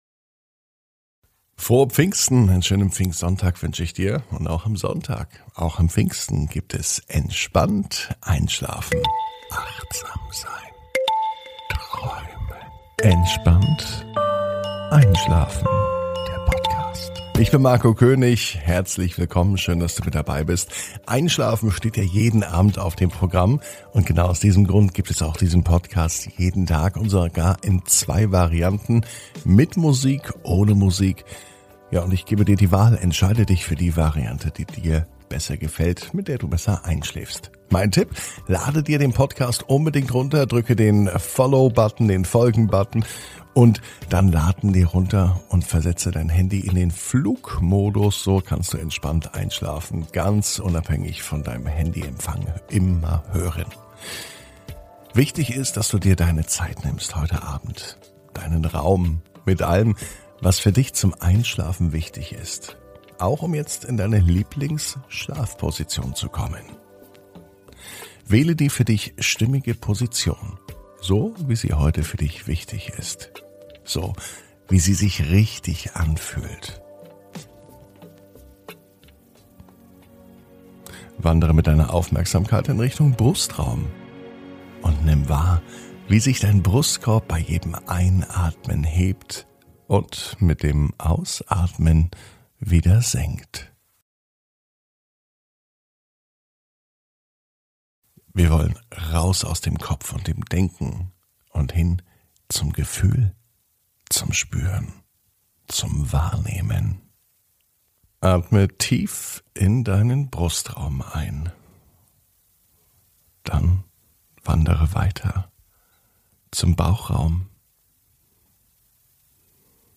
(Ohne Musik) Entspannt einschlafen am Sonntag, 23.05.21 ~ Entspannt einschlafen - Meditation & Achtsamkeit für die Nacht Podcast